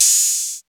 CYM XCHEEZ05.wav